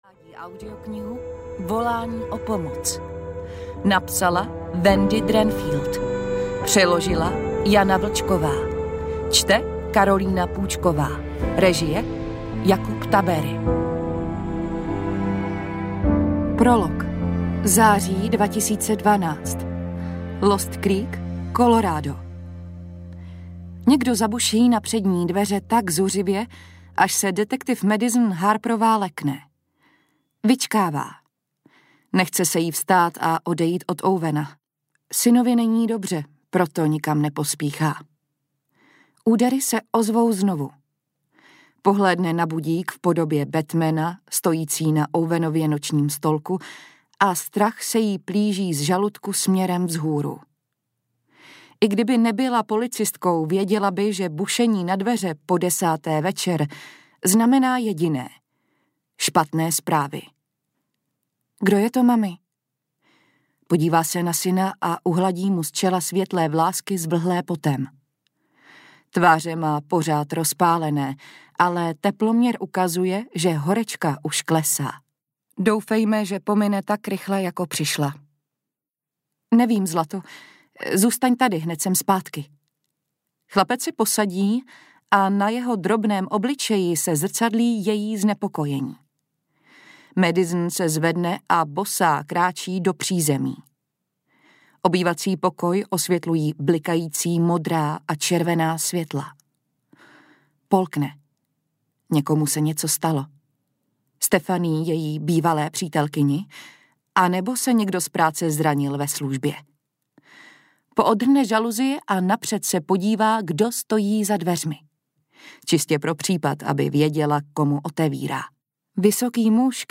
Volání o pomoc audiokniha
Ukázka z knihy